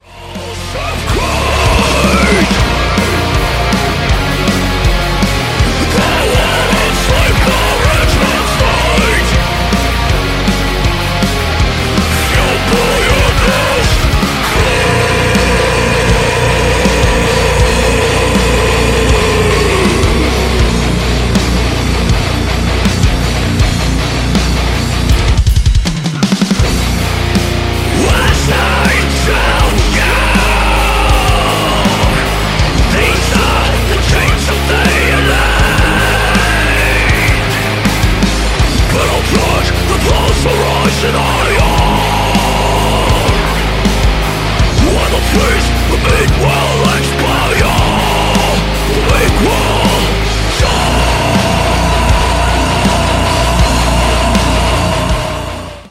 With crushing guitars, haunting atmospheres